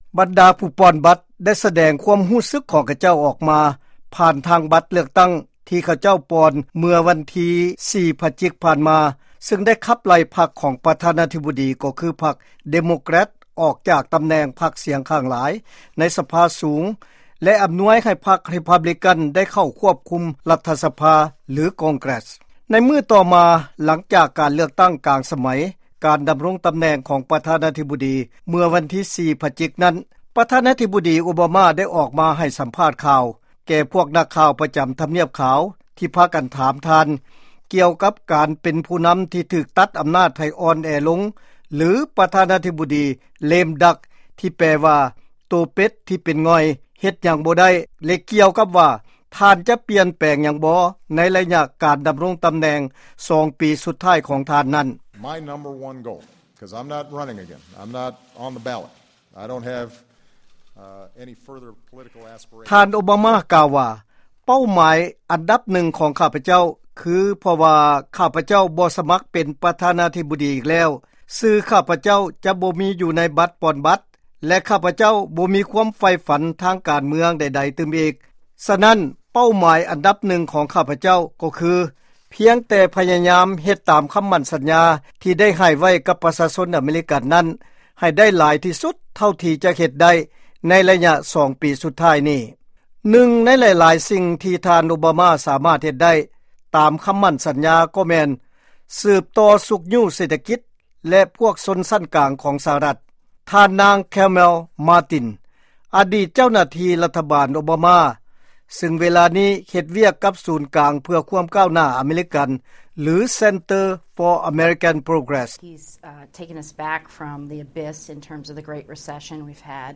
by ສຽງອາເມຣິກາ ວີໂອເອລາວ